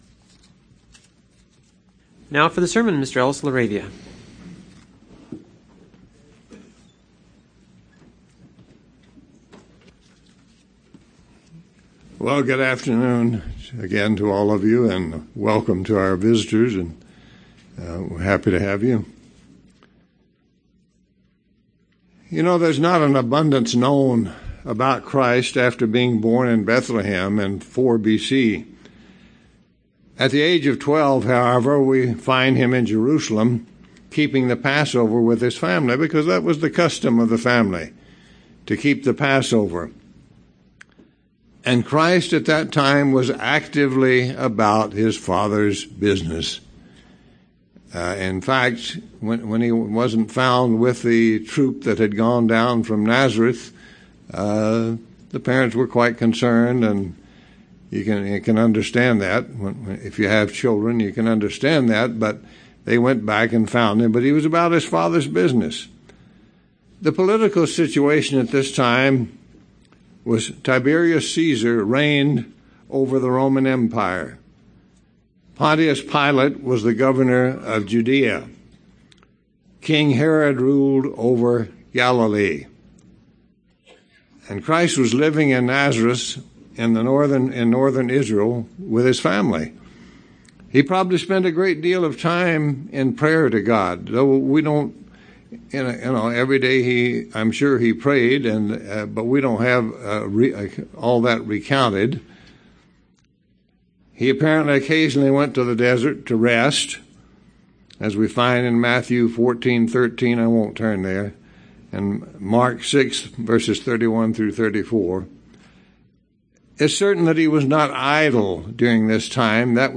(Luke 24:13-27) UCG Sermon Studying the bible?